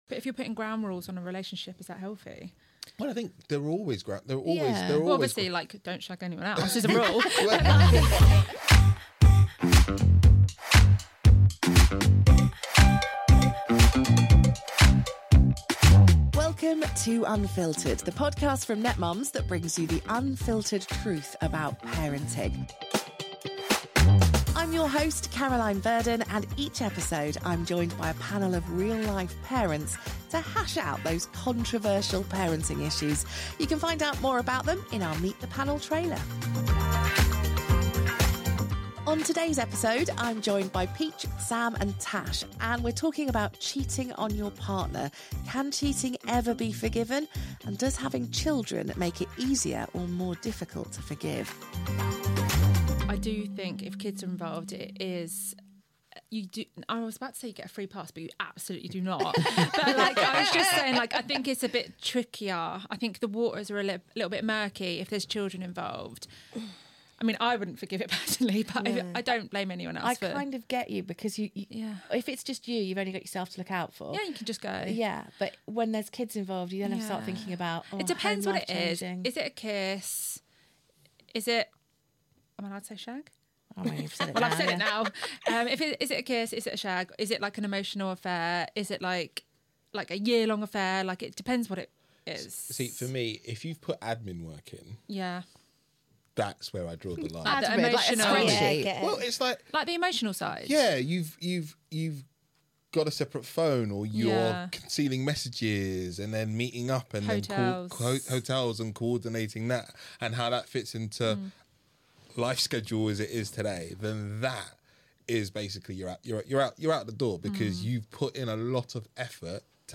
Join the conversation as our panel shares their candid experiences and perspectives on navigating infidelity, especially when children are involved. From emotional affairs to drunken kisses, discover where they draw the line and whether forgiveness is on the table.